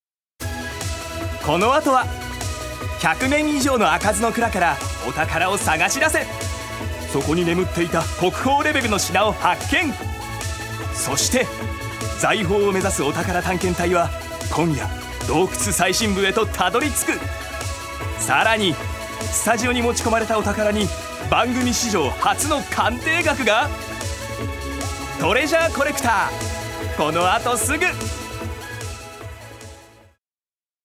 所属：男性タレント
ナレーション２